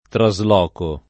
[ tra @ l 0 ko ]